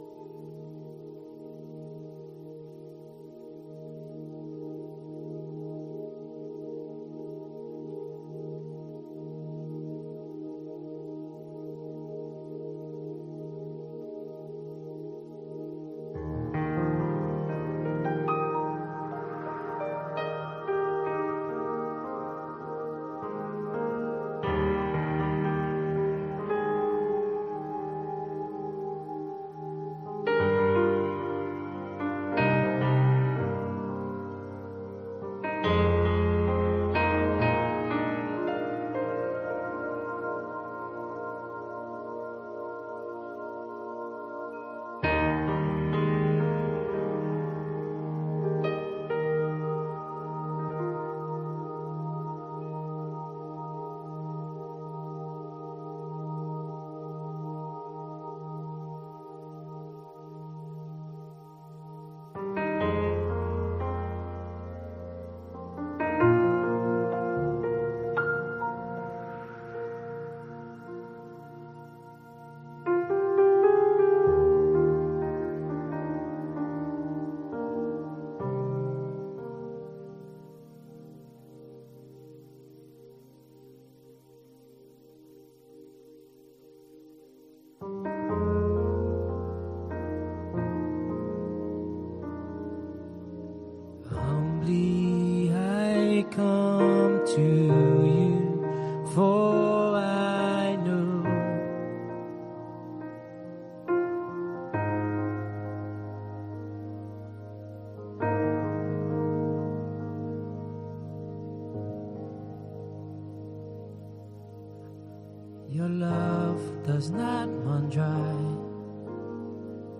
Soaking Prayer and Worship